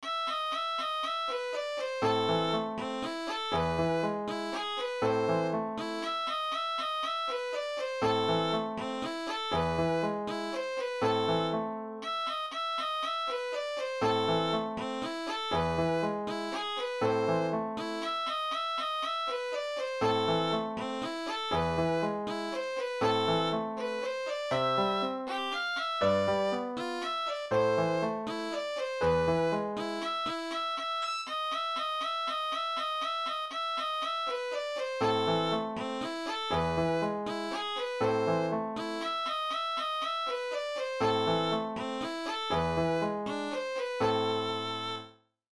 Für Elise (Beethoven) for Easy Violin Solo
Free printable sheet music for Für Elise by Ludwig van Beethoven for easy violin solo with piano accompaniment.
fur-elise-violin-solo.mp3